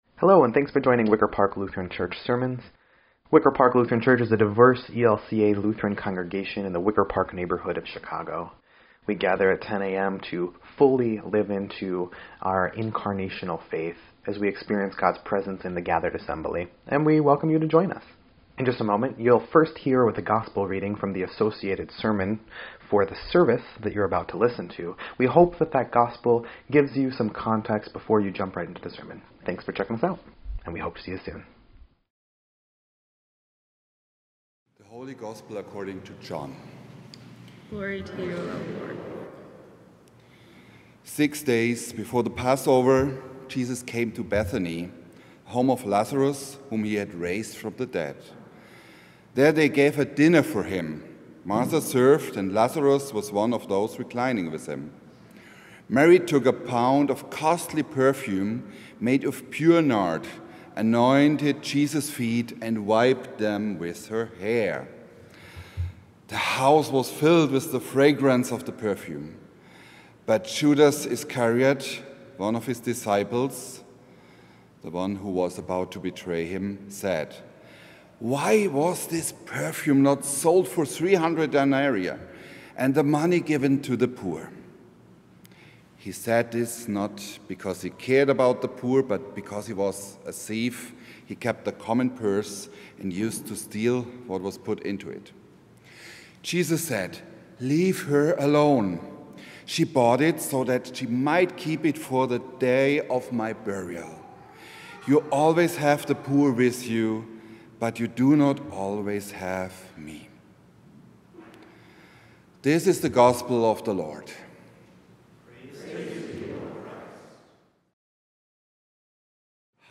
4.6.25-Sermon_EDIT.mp3